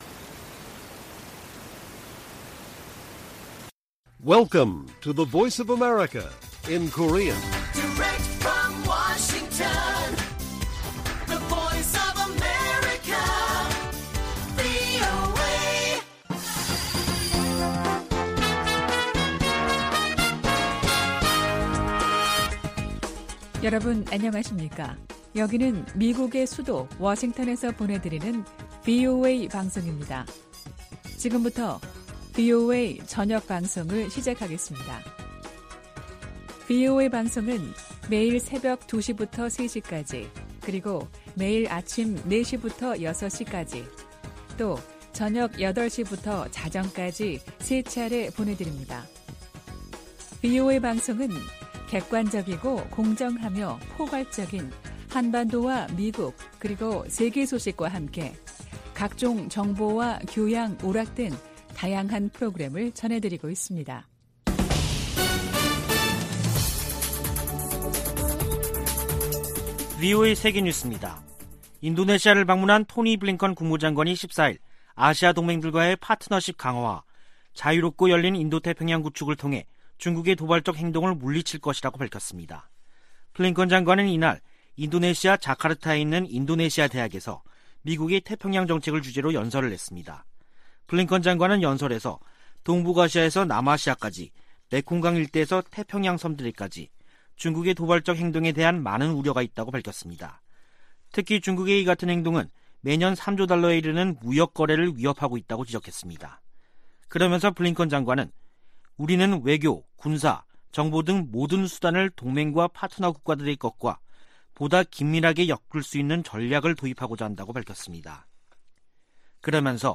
VOA 한국어 간판 뉴스 프로그램 '뉴스 투데이', 2021년 12월 14일 1부 방송입니다. 미 국무부는 베이징 동계올림픽 외교적 보이콧에 관해 ‘한국 스스로 결정할 일’이라고 밝혔습니다. 미 국방부는 한국군 전시작전통제권 전환을 위한 완전운용능력(FOC) 평가를 내년 여름에 실시하는 계획을 재확인했습니다. 미 재무부가 북한 내 인권 유린에 연루된 개인과 기관을 제재한 효과가 제한적일 것이라고 전문가들은 평가했습니다.